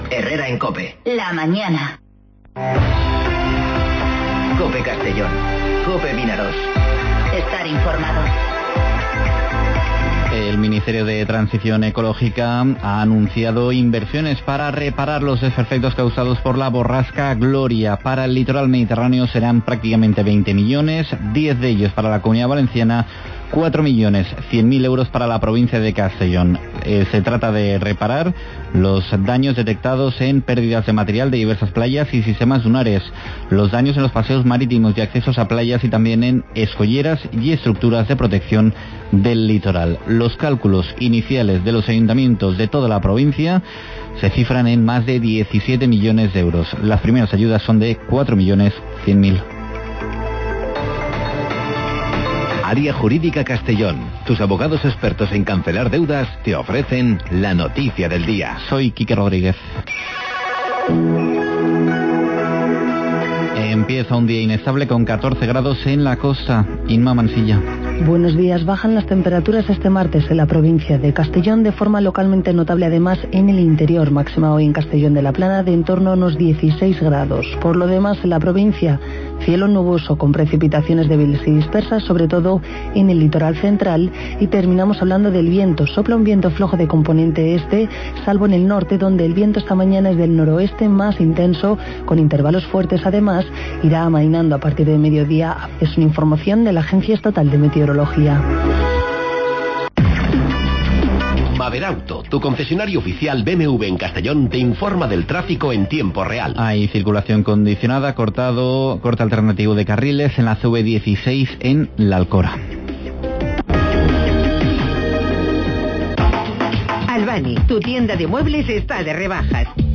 Informativo Herrera en COPE Castellón (18/02/2020)